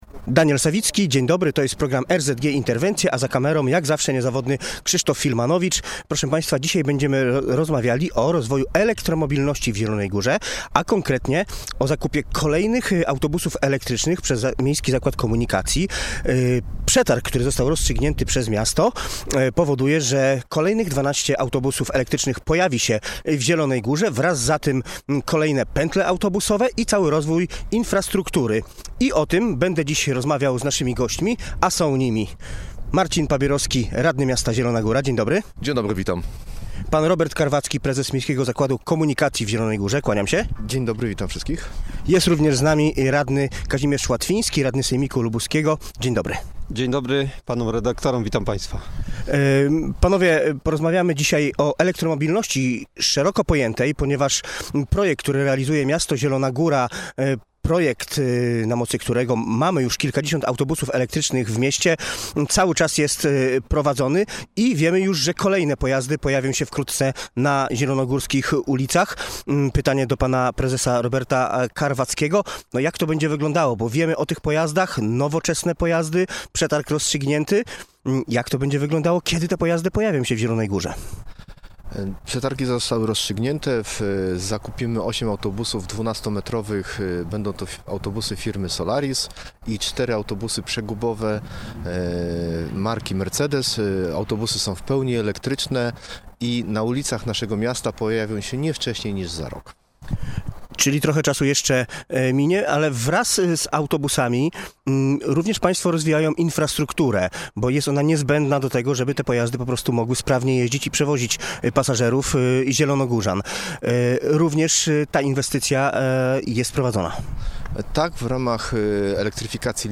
W mieście mają pojawić się przystanki kolejowe, a dworzec PKS będzie połączony z dworcem PKP. O tym dyskutowali uczestnicy wczorajszego programu RZG Interwencje: